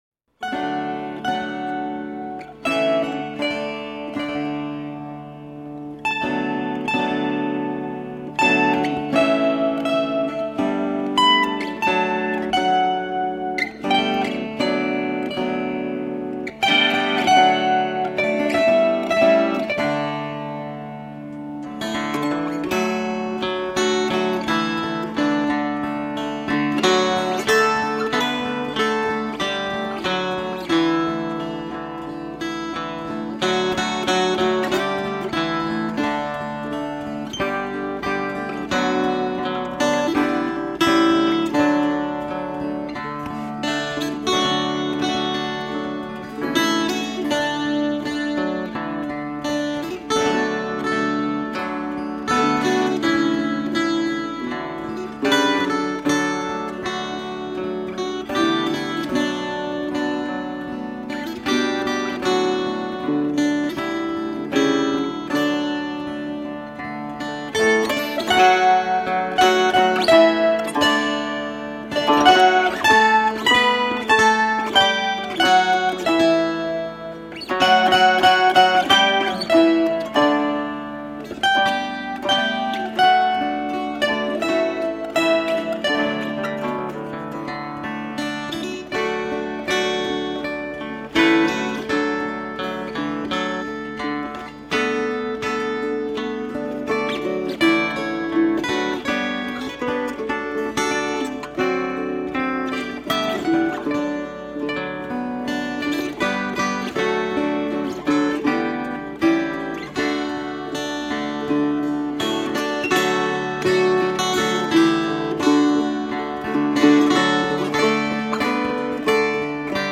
Smile-inducing, toe-tapping folkgrass.